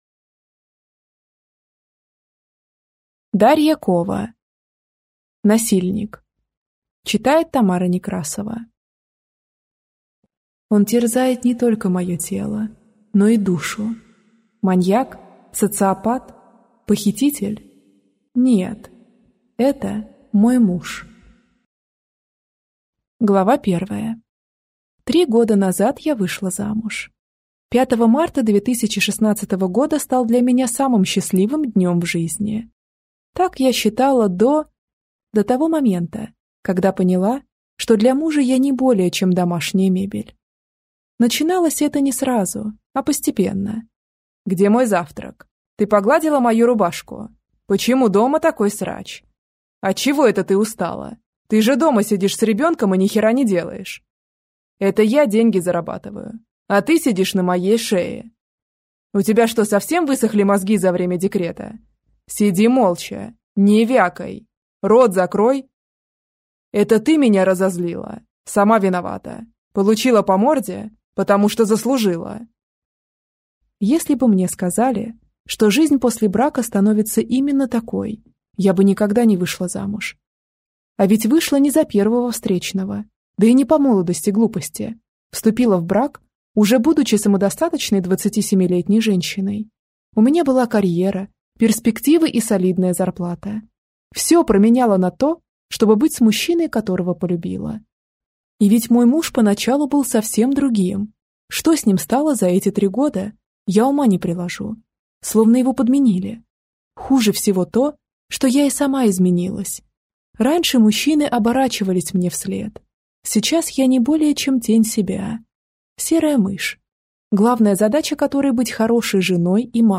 Аудиокнига Насильник | Библиотека аудиокниг